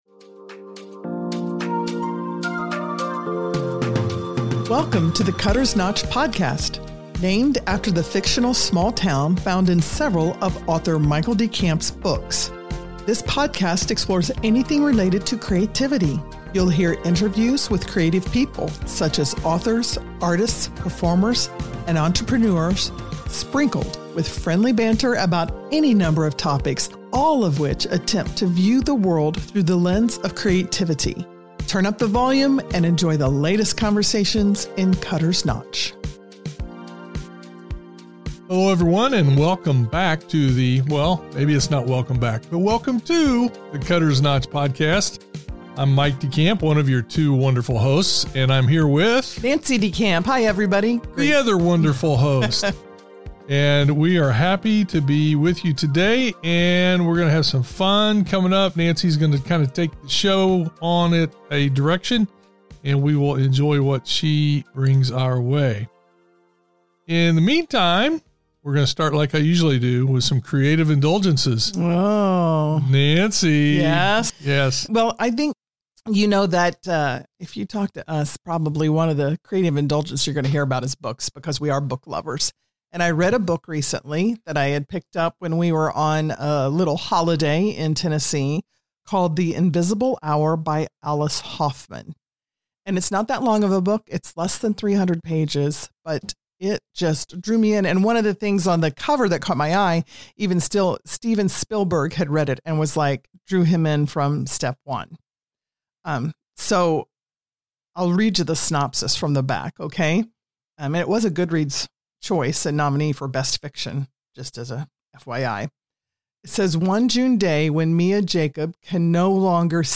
a lively discussion of various holiday memories and ideas.